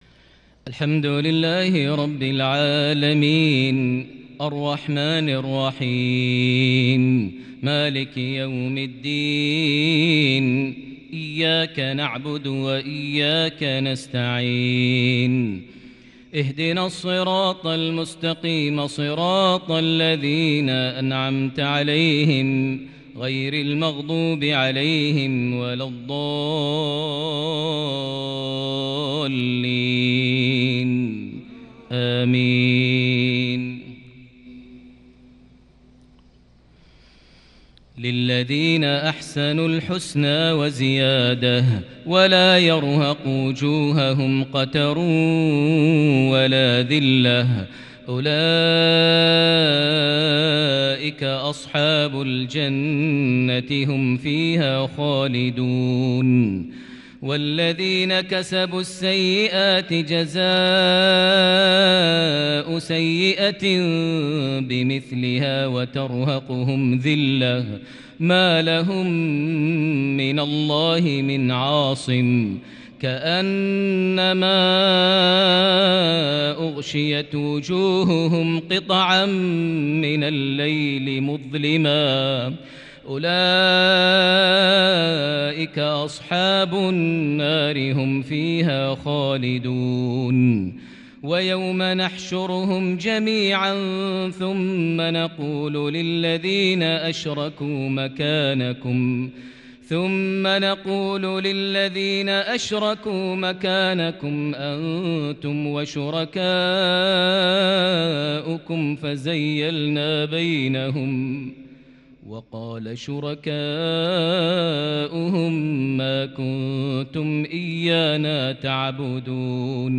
مغربية لاتوصف بتلاوة بديعة بالكرد من سورة يونس (26-35) | 21 جمادى الآخر 1442هـ > 1442 هـ > الفروض - تلاوات ماهر المعيقلي